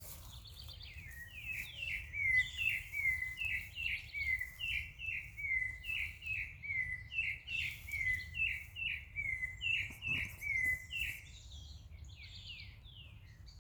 Sabiá-laranjeira (Turdus rufiventris)
Nome em Inglês: Rufous-bellied Thrush
Localidade ou área protegida: Reserva Privada San Sebastián de la Selva
Condição: Selvagem
Certeza: Observado, Gravado Vocal
Zorzal-colorado-Misiones_1.mp3